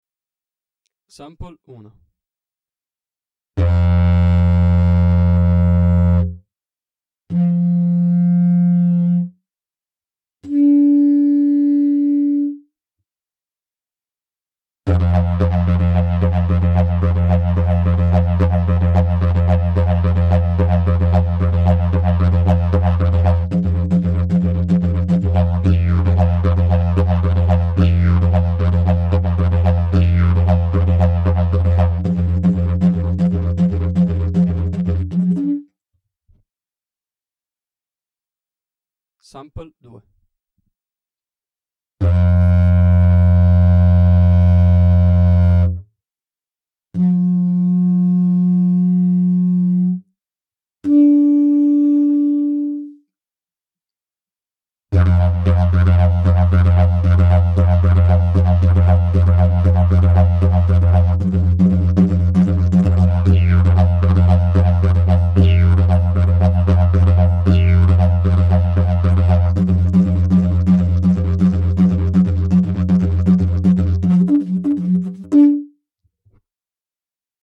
Puoi ascoltare una traccia audio in cui si possono sentire i due strumenti consecutivamente:
Il primo in resina, il secondo in castagno.
resina e legno confronto.mp3